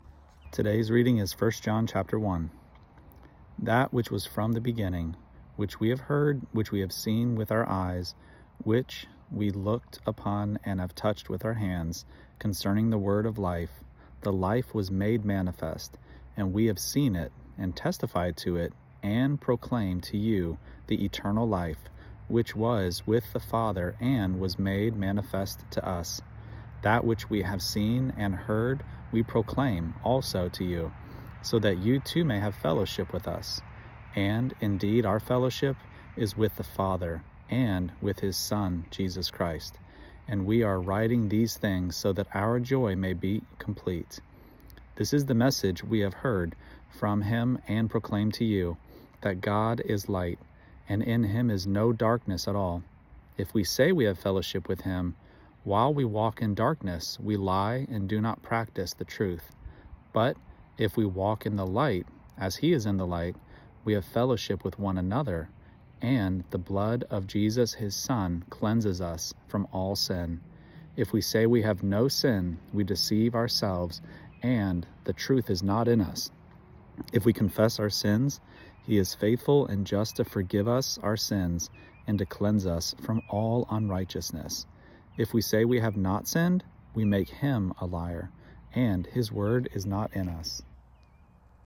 Daily Bible Reading (ESV) December 2: 1 John 1 Play Episode Pause Episode Mute/Unmute Episode Rewind 10 Seconds 1x Fast Forward 30 seconds 00:00 / 1:32 Subscribe Share Apple Podcasts Spotify RSS Feed Share Link Embed